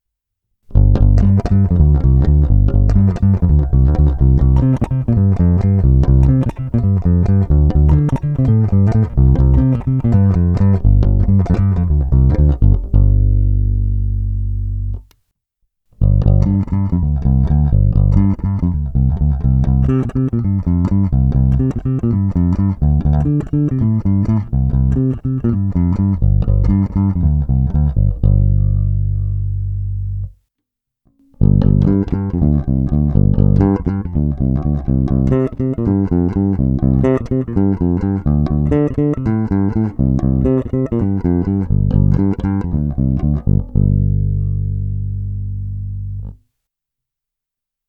Následující ukázky byly pořízeny s nylonovými hlazenými strunami D'Addario ETB92 Tapewound Bass s tloušťkami .050" až .105."
Nahrávky jsou jednotlivě normalizovány, jinak ponechány bez dodatečných úprav.
Kobylkový snímač
Samotný kobylkový snímač je vrčivější, použil bych ho asi jen na sóla, kdybych tedy vůbec nějaká hrál.